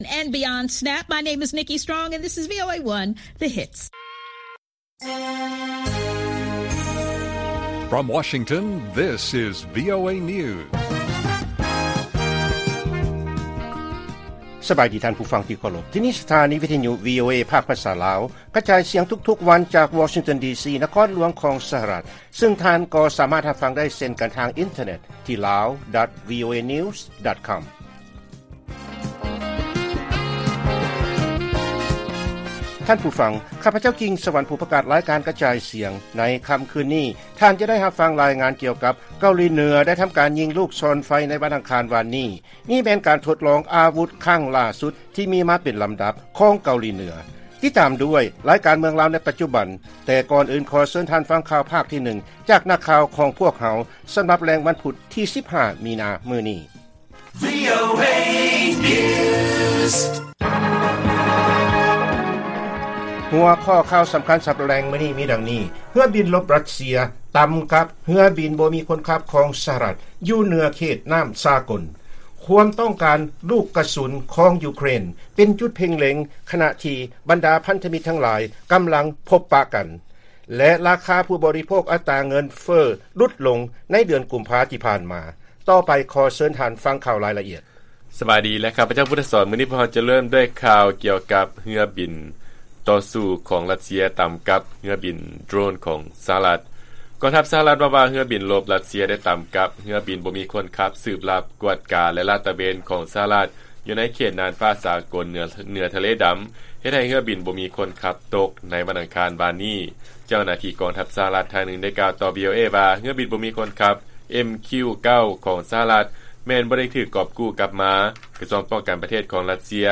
ລາຍການກະຈາຍສຽງຂອງວີໂອເອ ລາວ: ເຮືອບິນລົບ ຣັດເຊຍ ຕຳກັບ ເຮືອບິນບໍ່ມີຄົນຂັບຂອງ ສະຫະລັດ ຢູ່ເໜືອເຂດນ້ຳສາກົນ